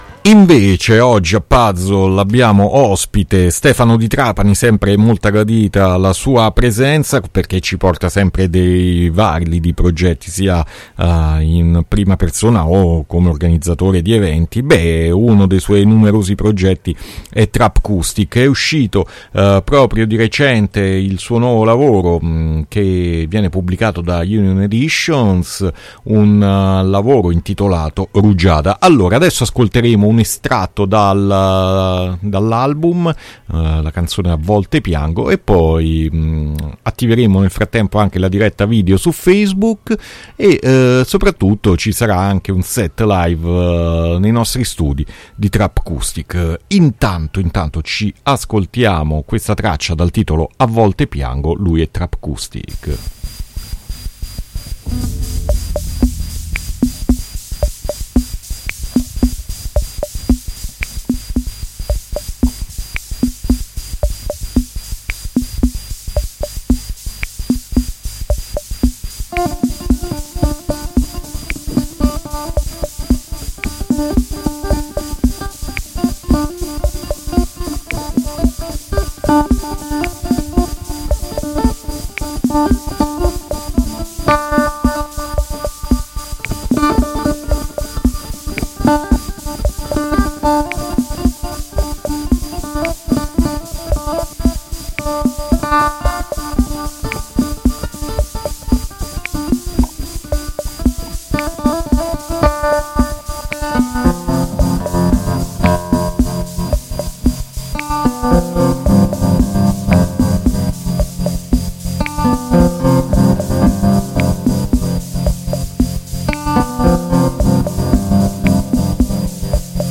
un’intervista arricchita anche da un mini live set